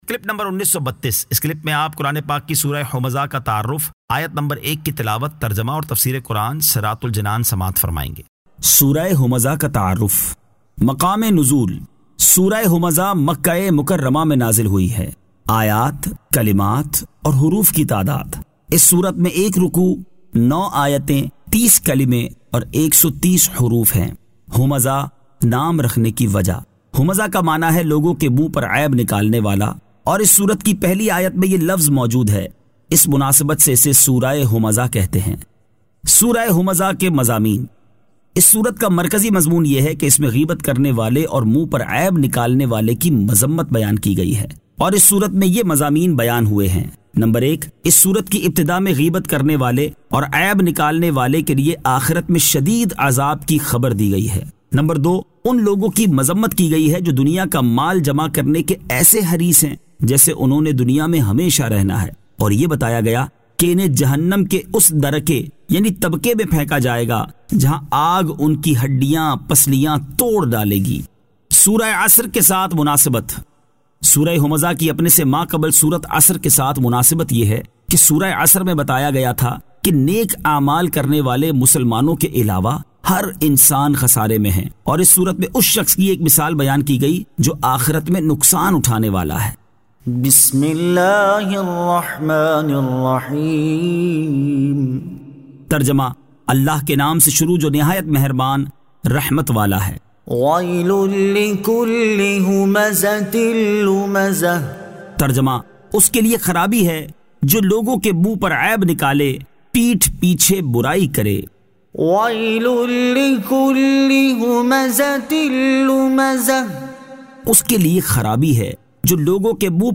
Surah Al-Humazah 01 To 01 Tilawat , Tarjama , Tafseer